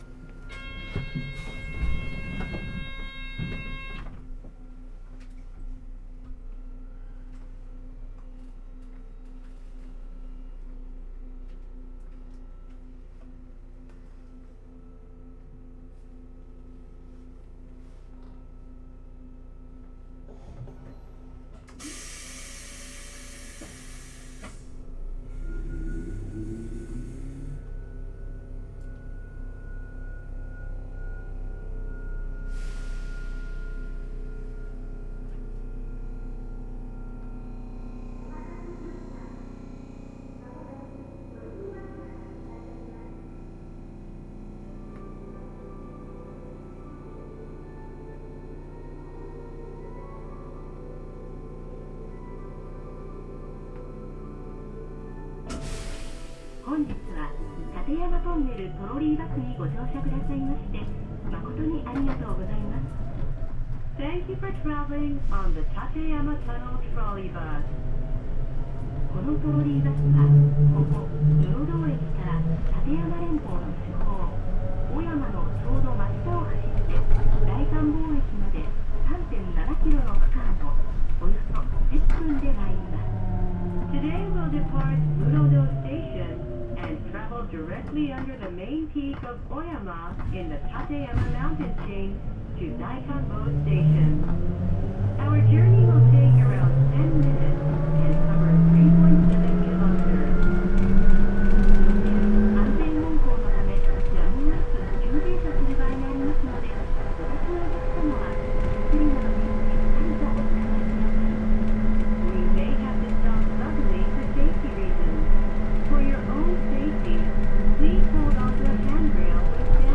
走行音(モータ付近)[tkk8000a.mp3/MP3-128kbps Stereo/8.08MB]
区間：室堂→信号場→大観峰 New!
※走行音録音のためのイベントでの録音です。2ファイルではマイクの場所や向きが違います。
種類：VVVFインバータ制御(東芝GTO第2世代、1C1M、発電ブレーキ)